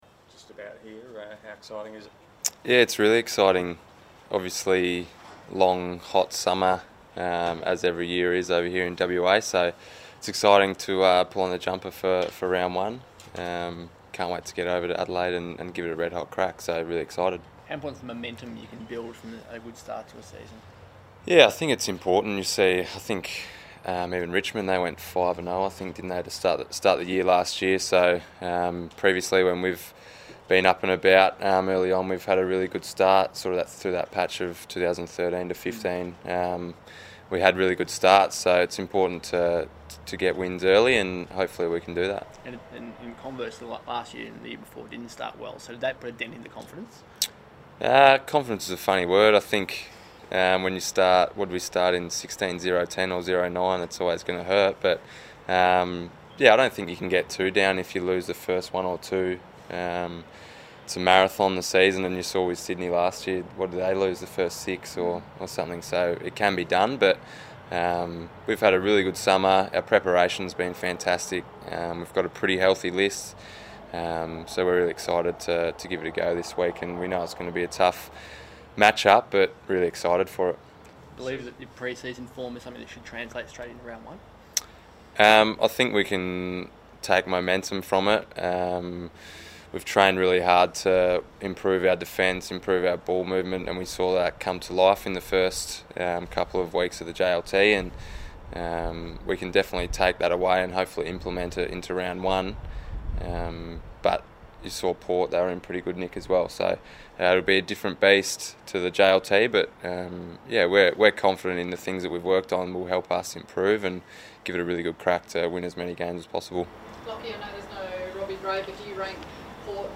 Lachie Neale press conference - Monday 19 March